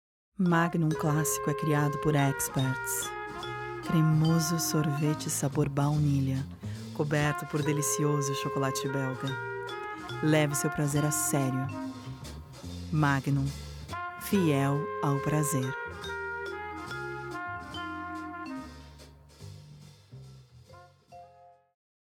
Feminino
Comercial sexy
Voz Padrão - Grave 00:22